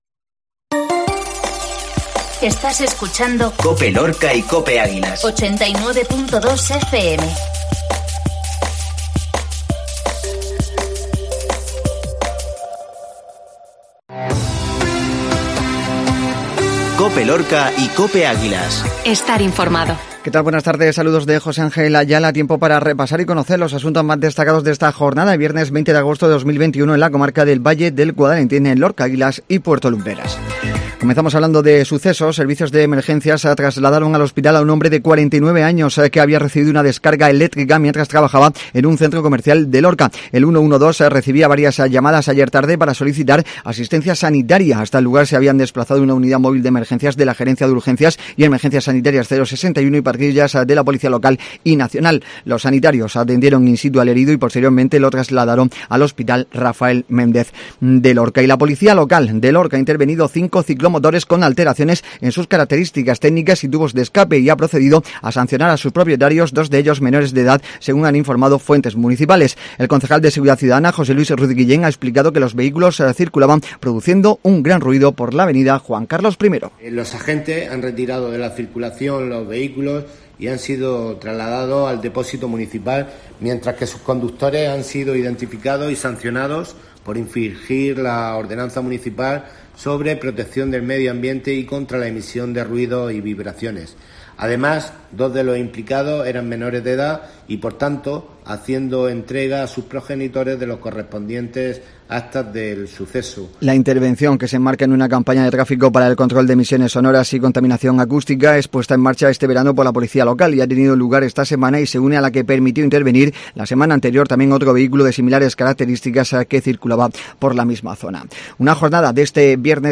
INFORMATIVO MEDIODÍA VIERNES